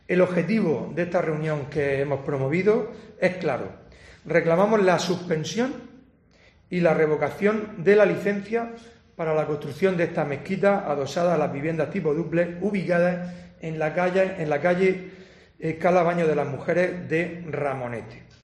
Fulgencio Gil, portavoz del PP